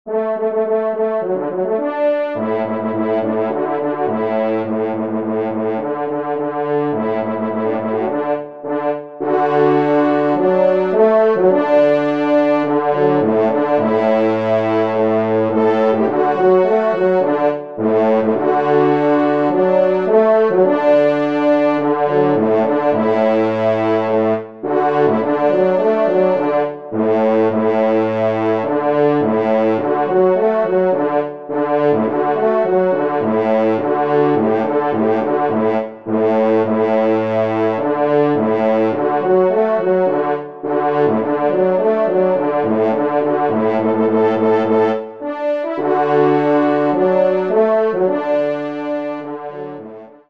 5e Trompe